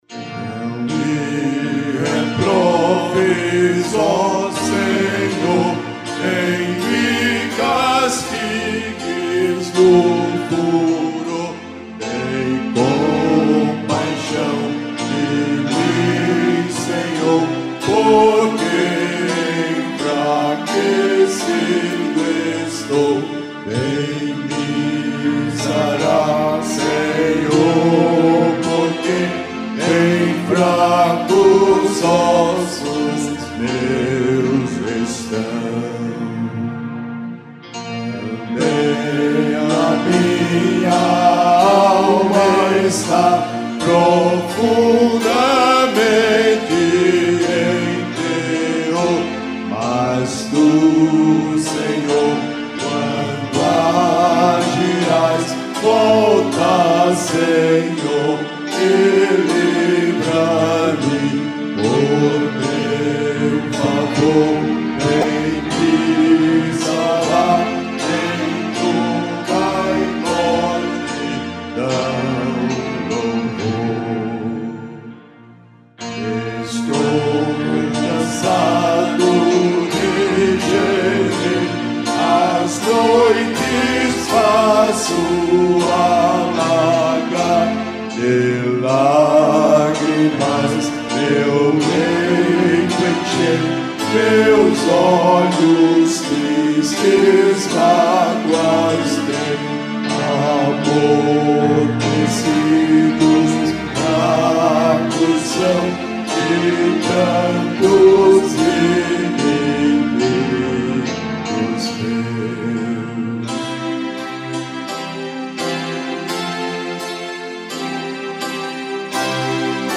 Métrica: 8 8. 8 8. 8 8
salmo_6B_cantado.mp3